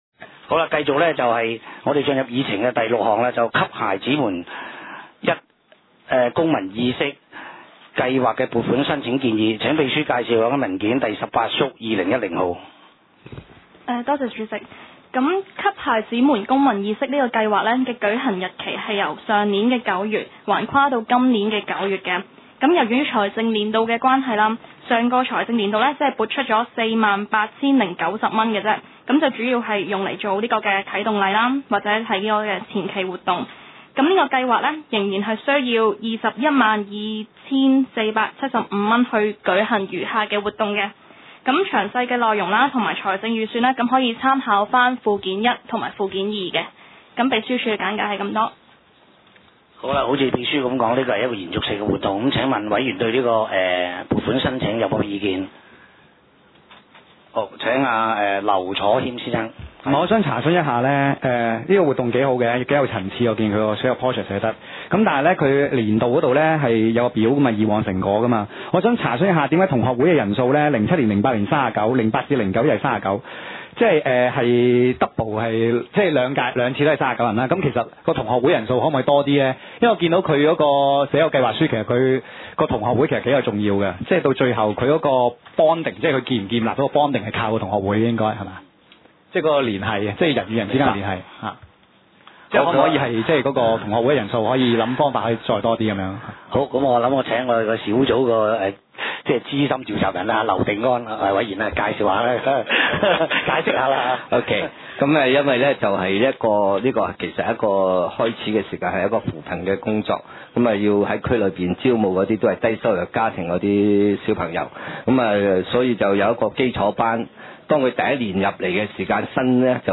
社會服務委員會第十八次會議記錄
九龍觀塘同仁街6號觀塘政府合署3樓觀塘民政事務處會議室